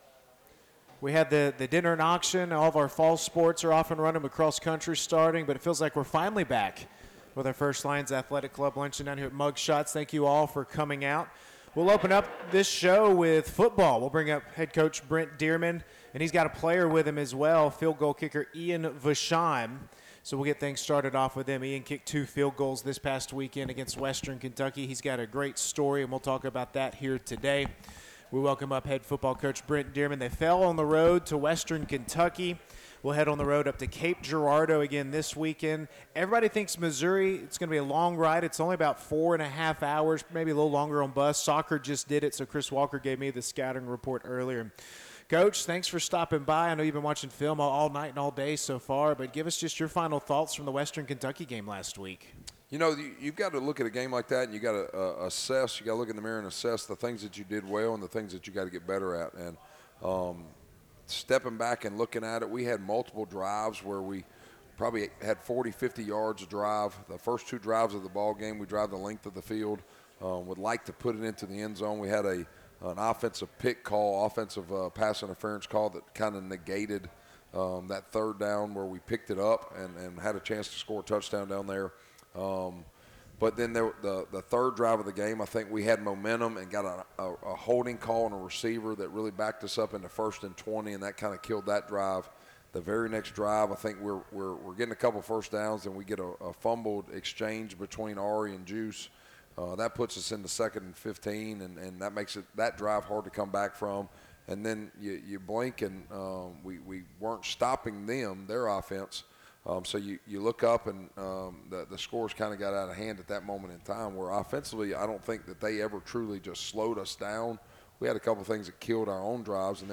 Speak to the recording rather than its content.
September 04, 2025 Hear from UNA head coach's and student athletes at the Lions Athletic Club Luncheon from Mugshots in downtown Florence.